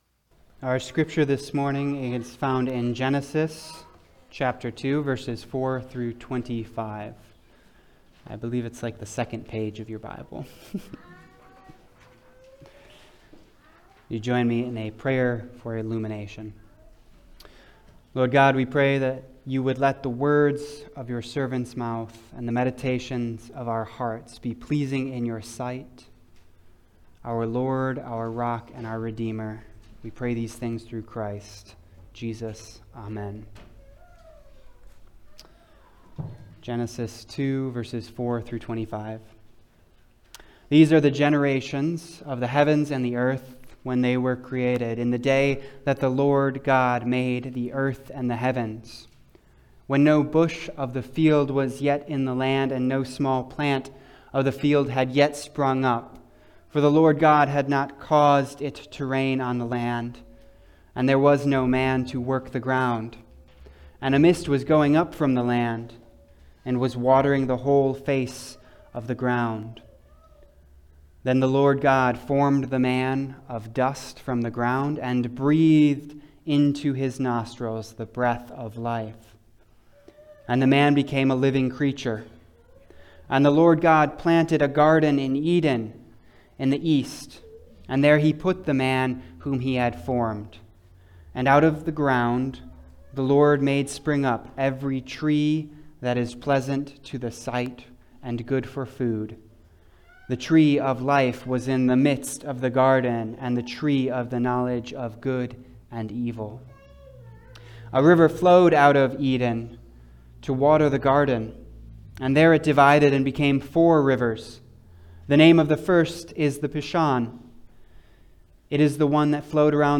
Passage: Genesis 2:4-24 Service Type: Sunday Service